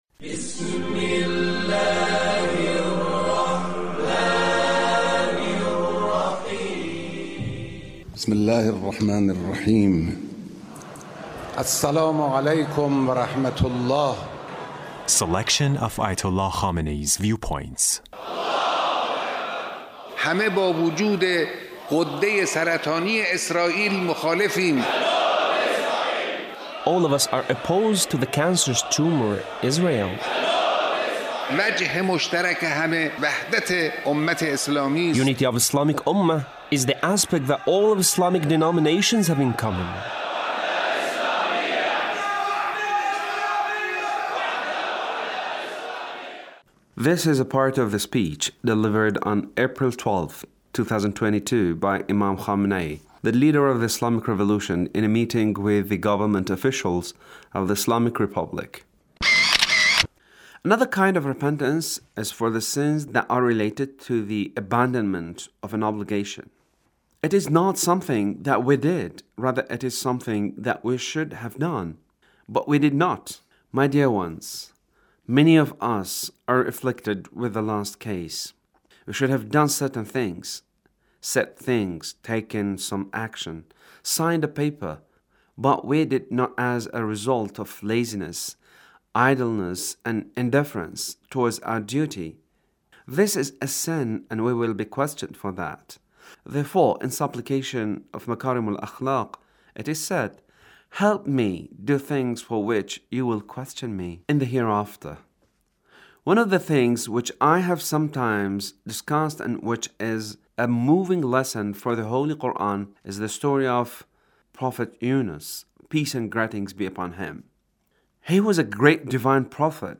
Leader's speech (1375)
The Leader's speech on Ramadhan